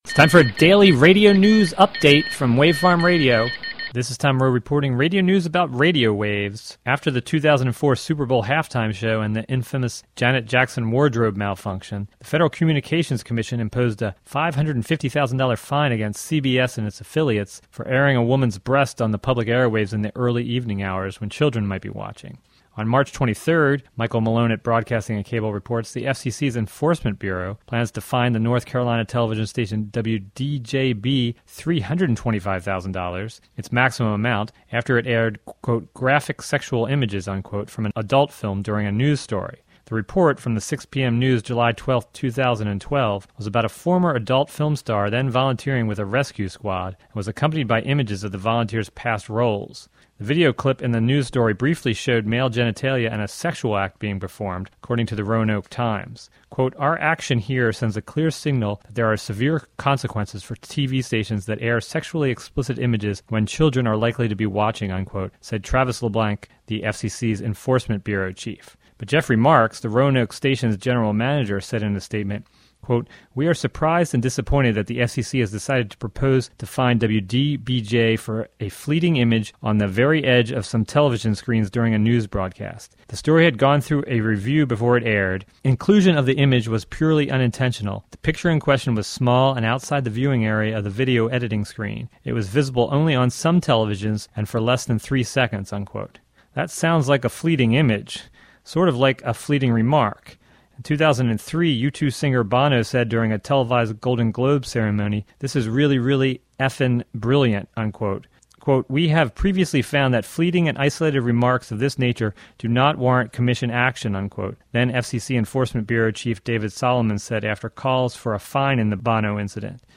Report about a new record obscenity fine for a North Carolina television station.